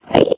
minecraft / sounds / mob / endermen / idle1.ogg